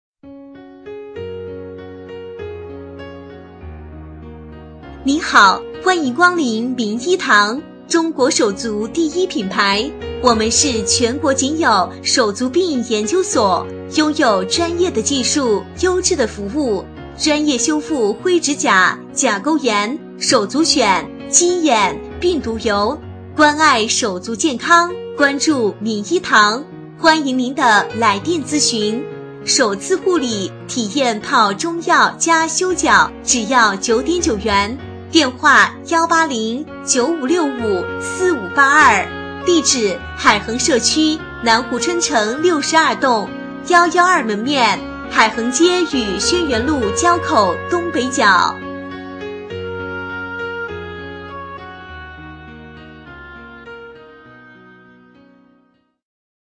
【女23号抒情播报】名医堂
【女23号抒情播报】名医堂.mp3